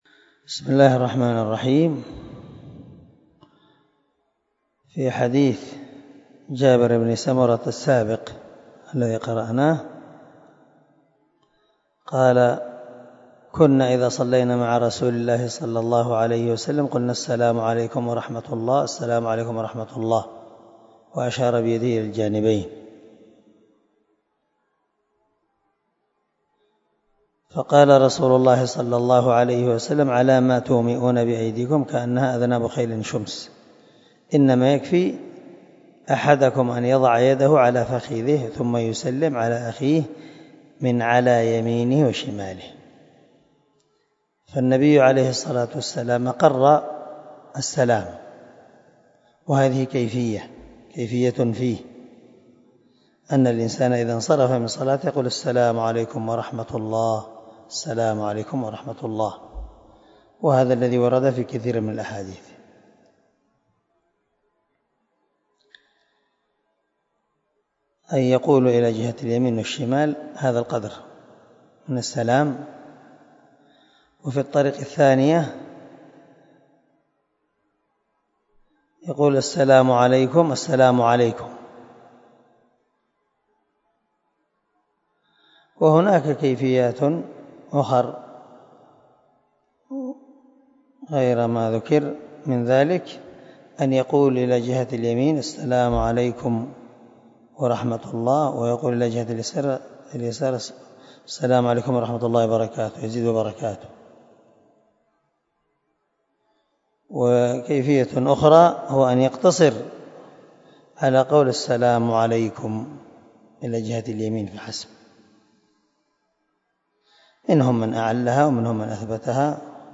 دار الحديث- المَحاوِلة- الصبيحة